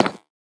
Minecraft Version Minecraft Version snapshot Latest Release | Latest Snapshot snapshot / assets / minecraft / sounds / block / nether_bricks / step4.ogg Compare With Compare With Latest Release | Latest Snapshot
step4.ogg